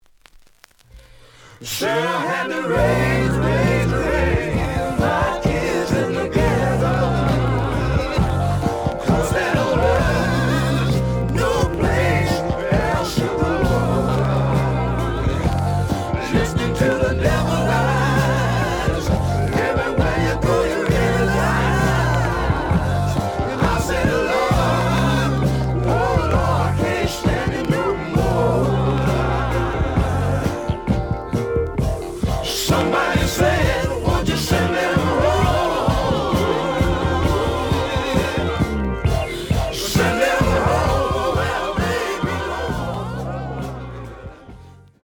試聴は実際のレコードから録音しています。
The audio sample is recorded from the actual item.
●Genre: Funk, 70's Funk